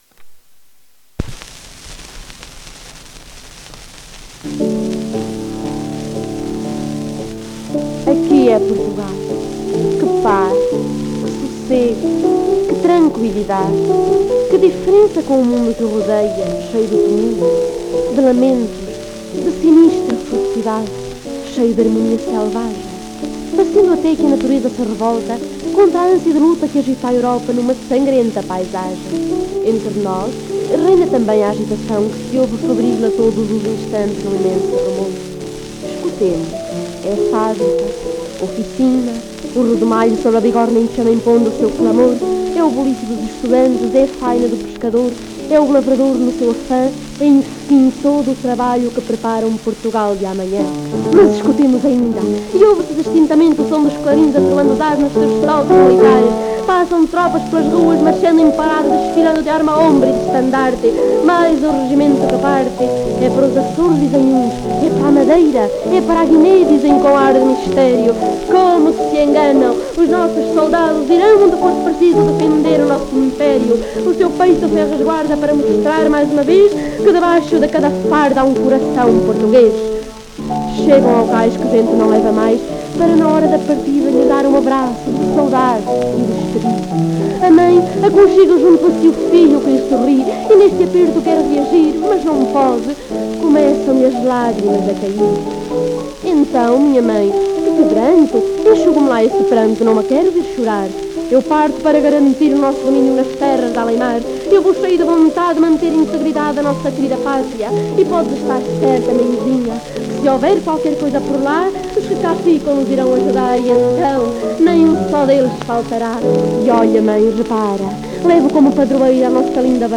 inetmd-fcsh-ifpxx-mntd-audio-aqui_e_portugal_recitado-1123.mp3